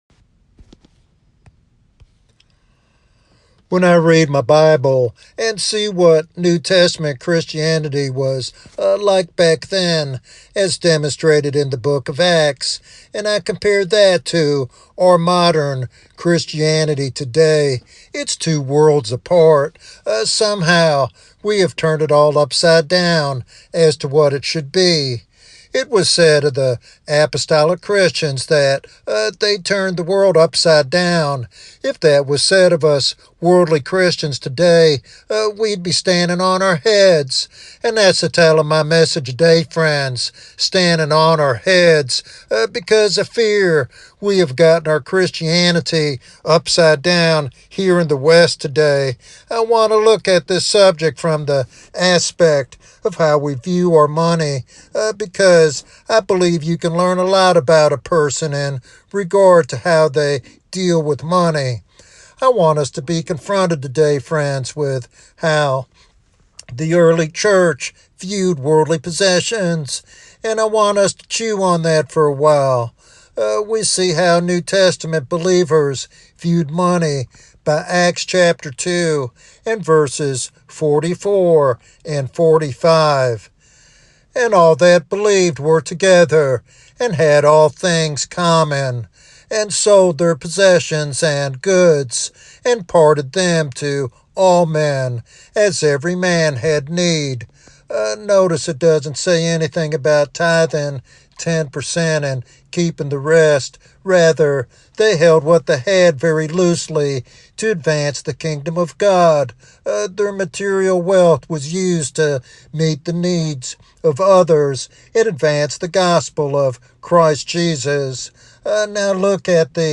This topical sermon calls for a return to authentic Christian stewardship and sacrificial living.